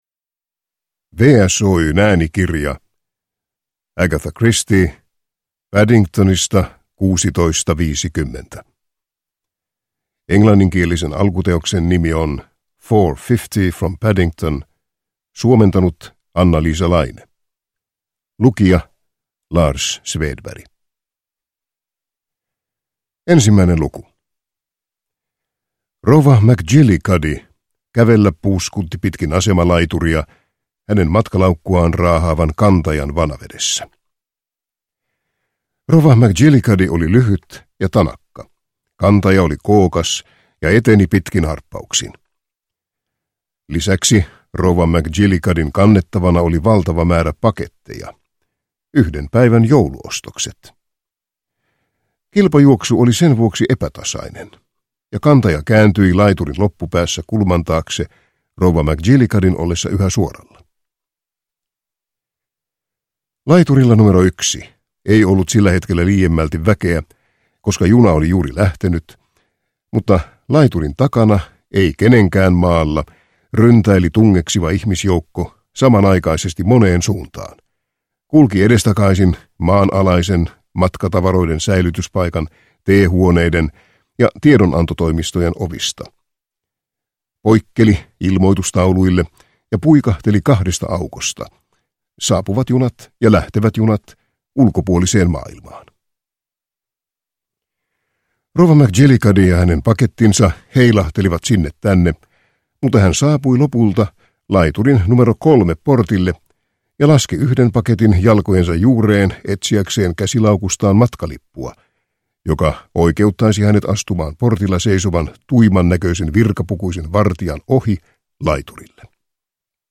Paddingtonista 16:50 – Ljudbok – Laddas ner